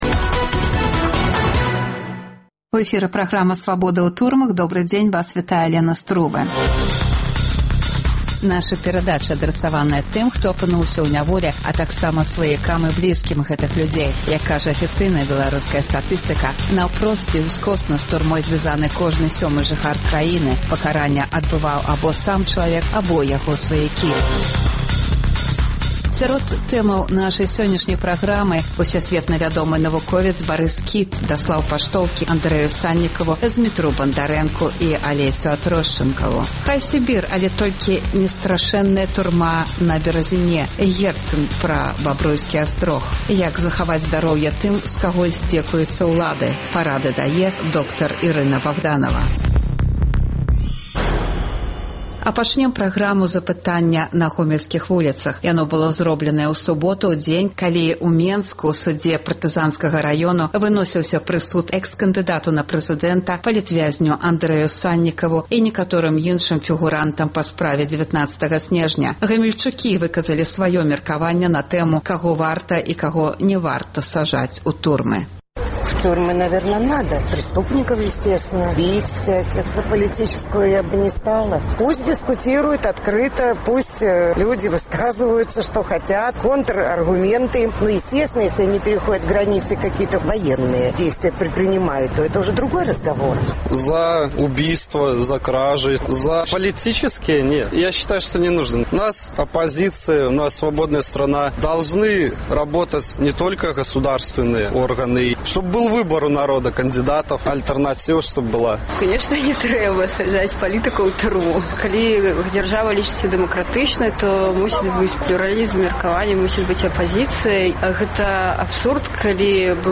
Апытаньне на вуліцах Гомеля.